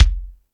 Lotsa Kicks(25).wav